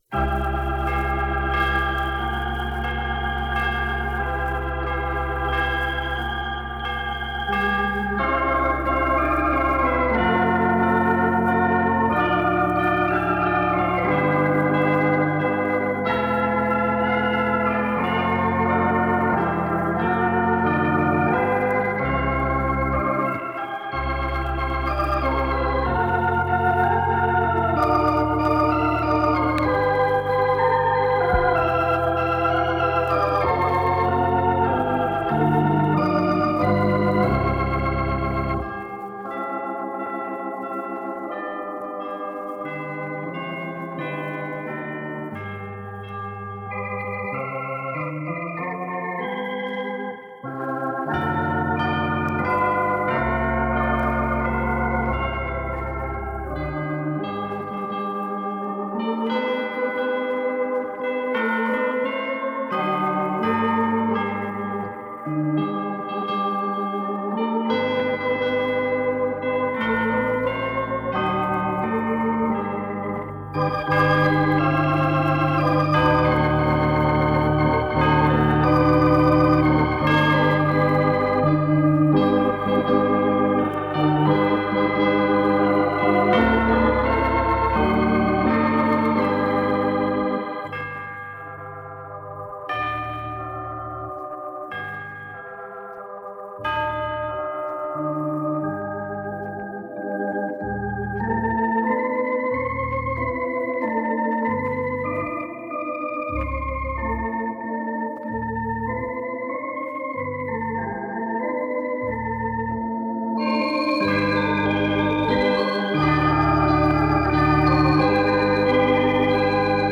Blogmas 2012 Day Four - Organ and Chimes
There certainly wouldn't have been much expense producing them - minimal recording set-up, minimalist cover art, no songwriter royalties.